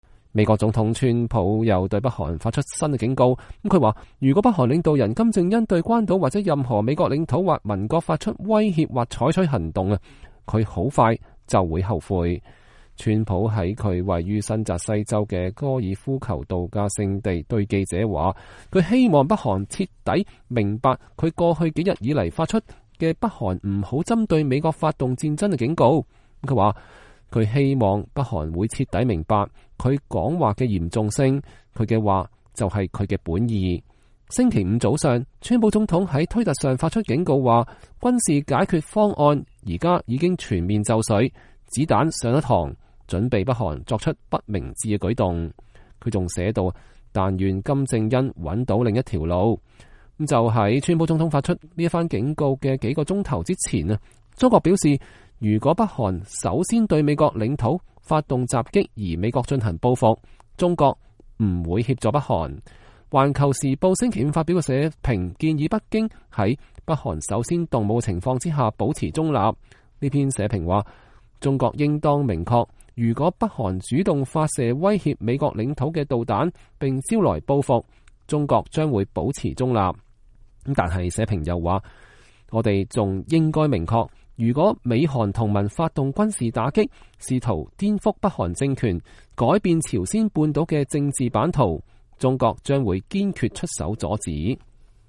川普總統在他位於新澤西州的高爾夫球渡假地對傳媒談北韓威脅 （2017年8月11日）